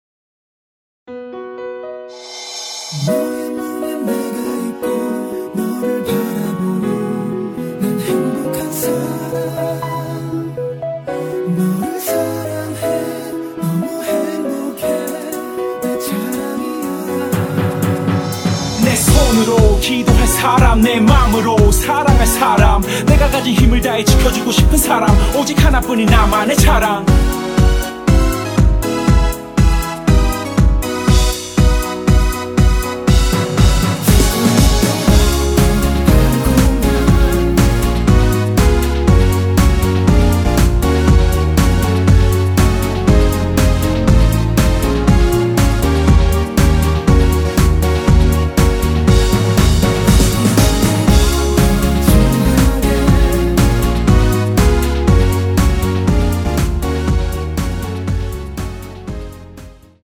전주 없이 시작 하는곡이라 전주 1마디 만들어 놓았습니다.
원키 코러스및 랩 포함된 MR 입니다.(미리듣기 참조)
앞부분30초, 뒷부분30초씩 편집해서 올려 드리고 있습니다.